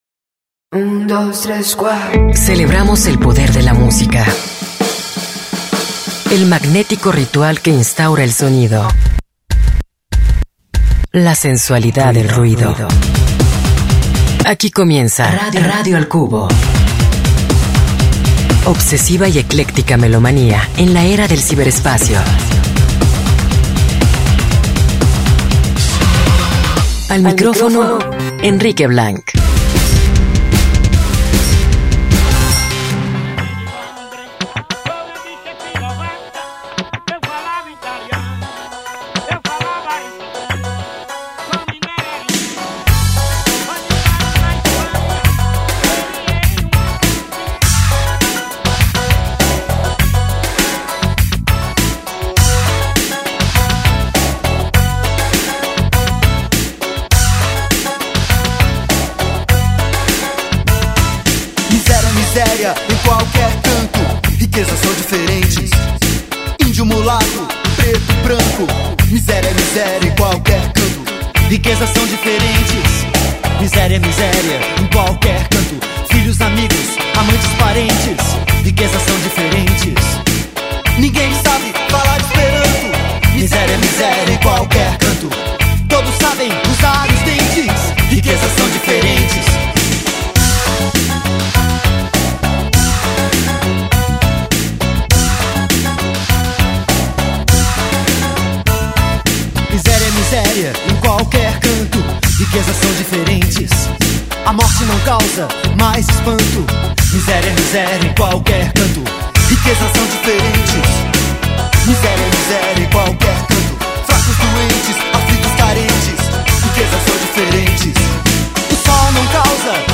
musica para continuar una buena semana